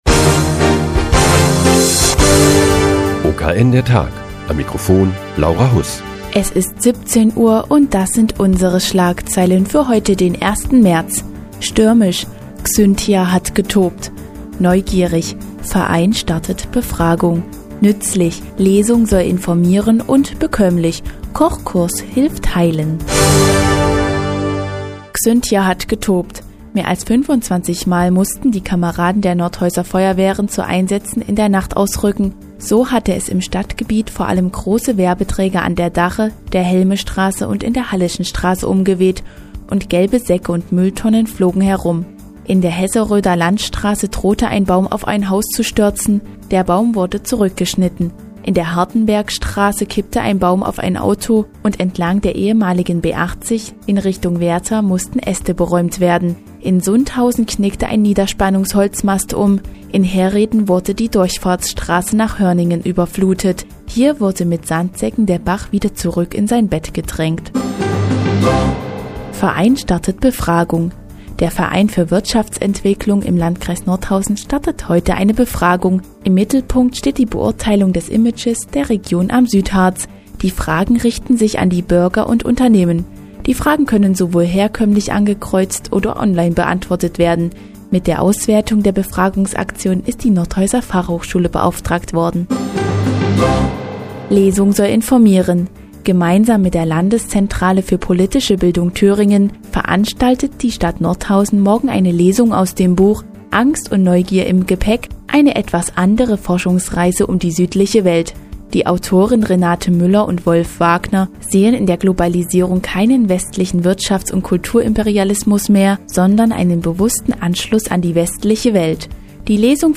Die tägliche Nachrichtensendung des OKN ist nun auch in der nnz zu hören. Heute geht es um eine Befragung zum Image der Region am Südharz und einen heilenden Kochkurs der Kreisvolkshochschule.